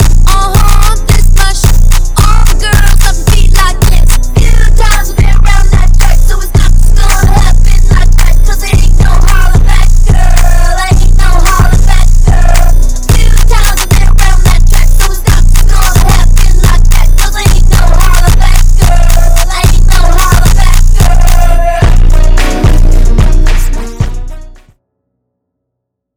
jerk remix
2nd beat goes so hard